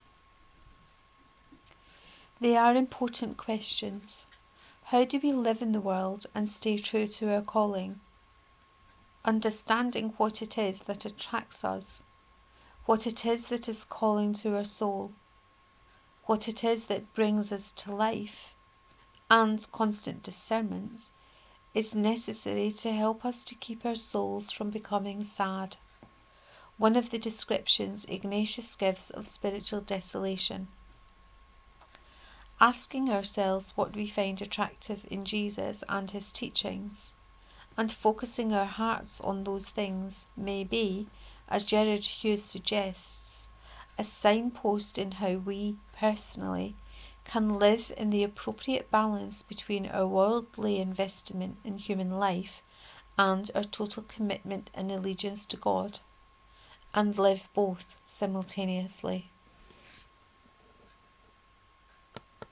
What do you find attractive about Jesus? 4: Reading of this post.